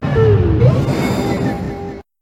Catégorie:Cri Pokémon (Soleil et Lune) Catégorie:Cri de Bamboiselle